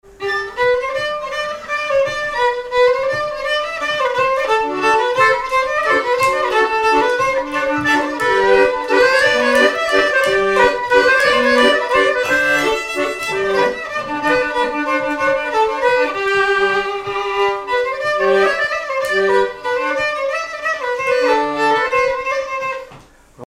Localisation Miquelon-Langlade
danse : mazurka-valse
violon
Pièce musicale inédite